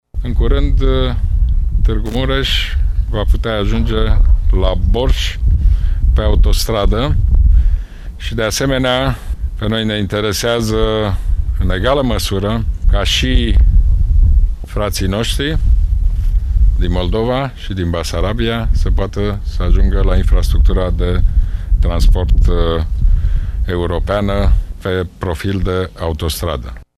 Așa a declarat, azi, premierul Ludovic Orban, la inaugurarea lotului 3 al Autostrăzii Transilvania, între Iernut şi Cheţani.